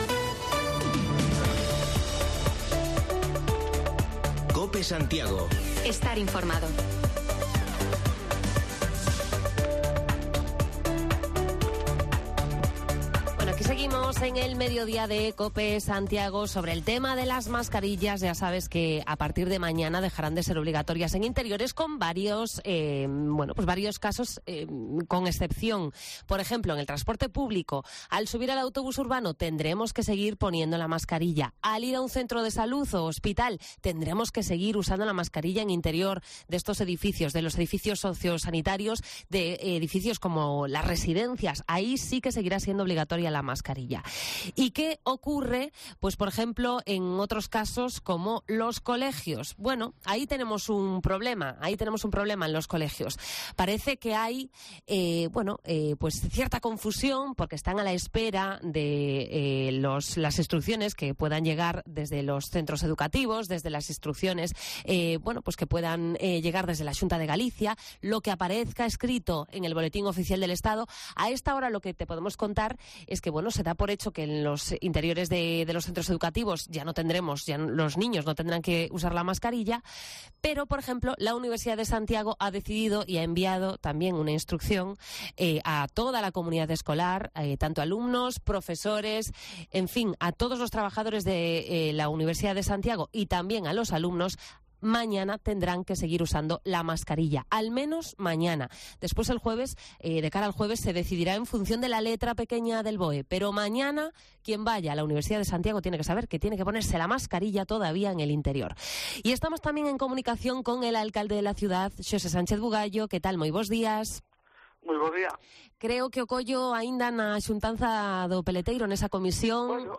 Repasamos la actualidad local en Santiago en la entrevista quincenal con el alcalde Sánchez Bugallo, con cuestiones que nos trasladan los oyentes de Cope